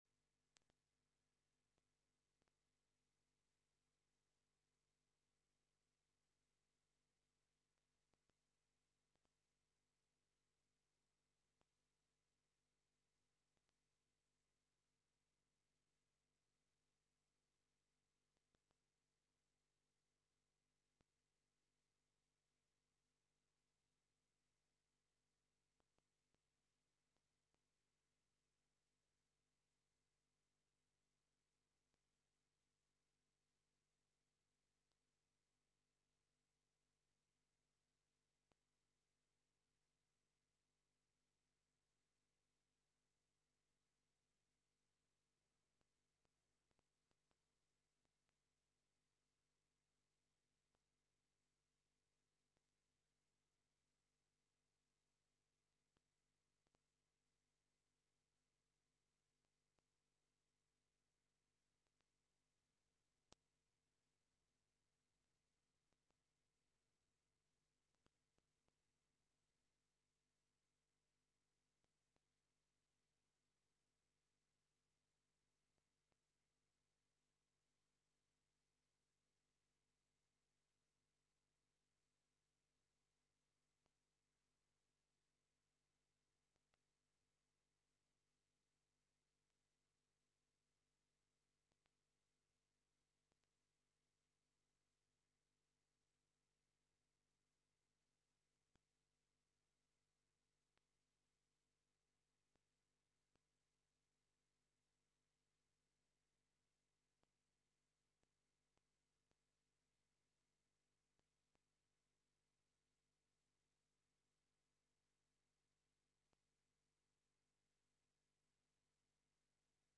Xəbər-ətər: xəbərlər, müsahibələr və İZ: mədəniyyət proqramı